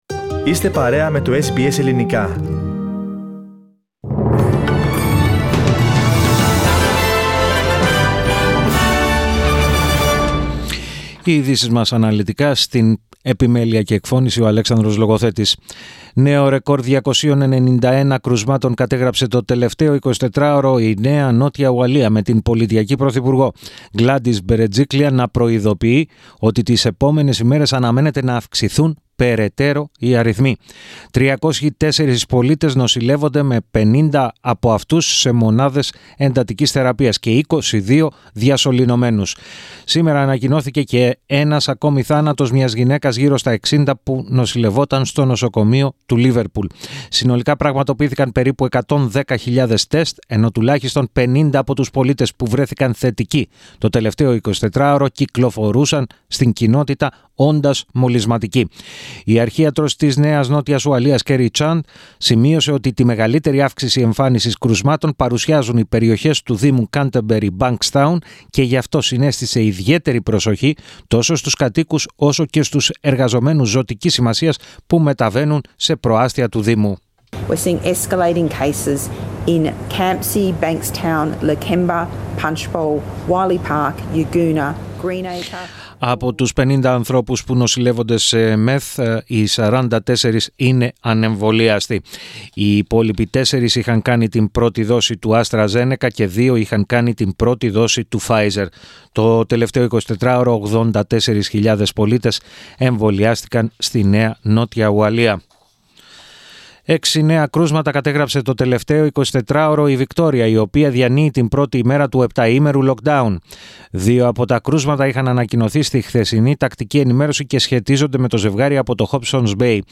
Ειδήσεις 06.08.21